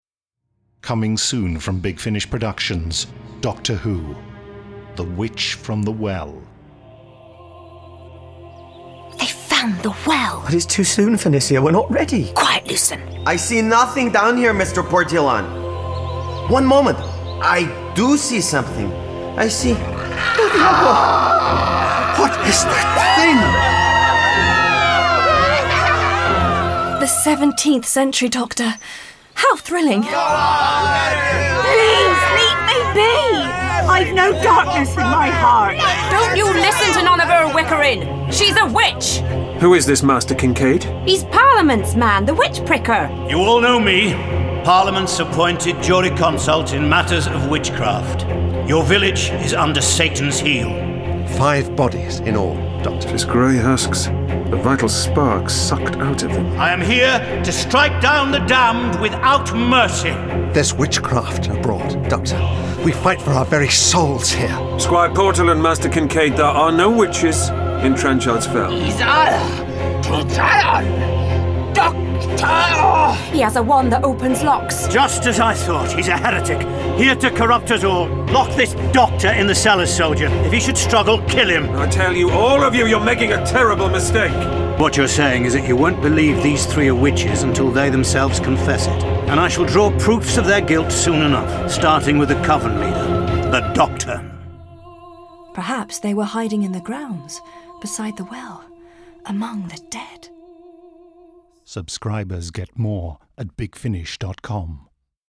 Starring Paul McGann Julie Cox